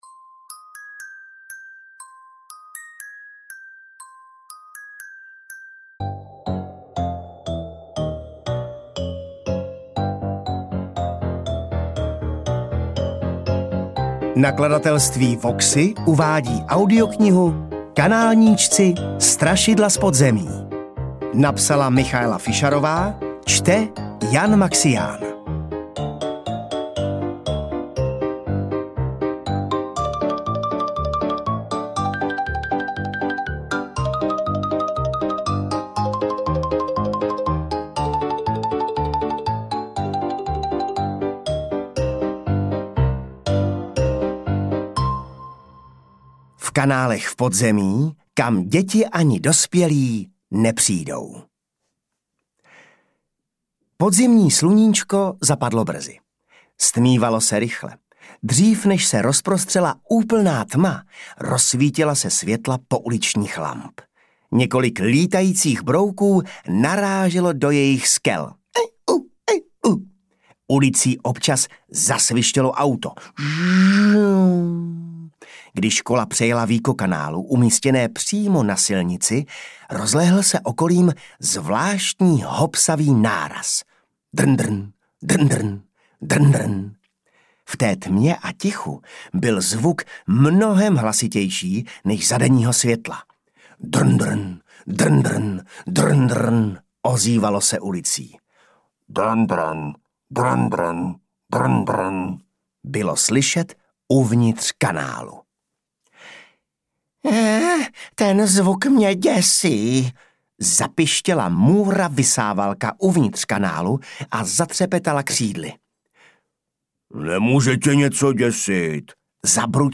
Kanálníčci: Strašidla z podzemí audiokniha
Ukázka z knihy
• InterpretJan Maxián